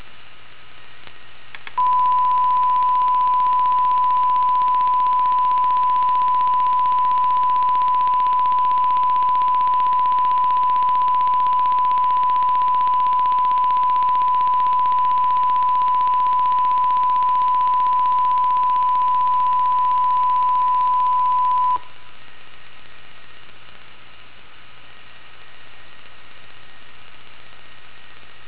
基本上、似乎我在 IN1L 和 IN2L 上采样的数字化音频上有一个快速咔嗒声。
附件中有三个音频文件、我的麦克风在其中录制1kHz 的声音。
在这三种模式中、都是1kHz 的记录。
所有三个录音都是通过使用扬声器旁边的麦克风播放我的 PC 扬声器的1kHz 声音来完成的。
文件1： CONSTANT_1kHz.WAV
基波频率为~15Hz、谐波一直扩展到大约1kHz。
CONSTANT_5F00_1kHz.WAV